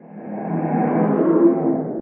guardian_idle2.ogg